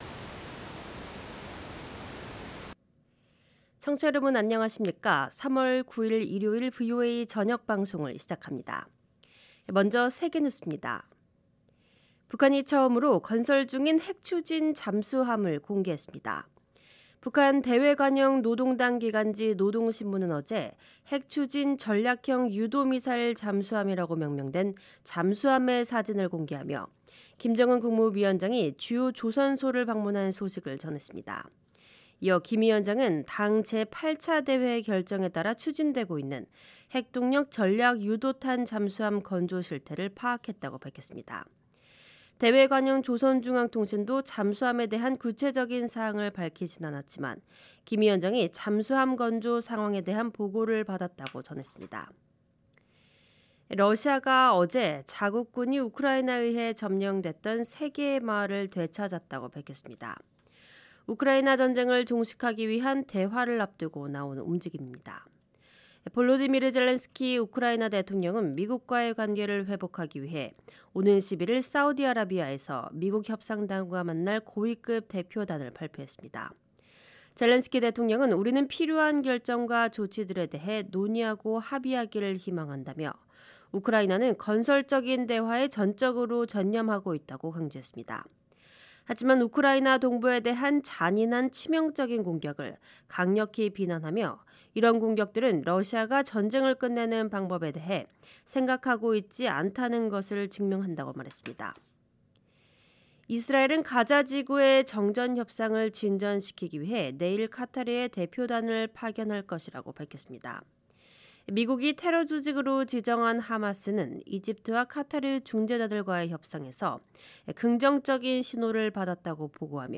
VOA 한국어 방송의 일요일 오후 프로그램 1부입니다.